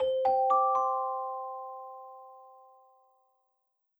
"Pin-pon-pan-pon" playing from the announcement system in Paper Mario: The Origami King
PMTOK_Announcement.wav